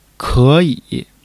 ke3-yi3.mp3